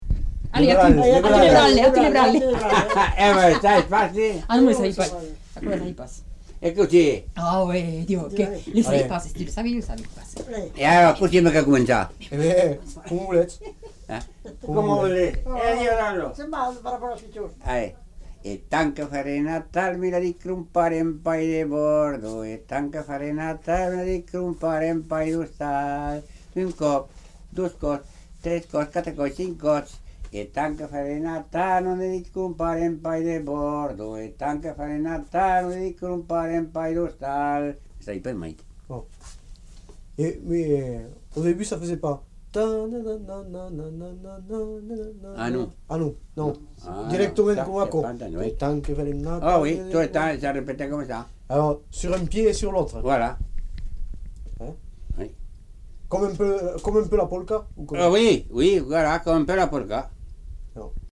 Lieu : Villaudric
Genre : chant
Effectif : 1
Type de voix : voix d'homme
Production du son : chanté
Danse : branle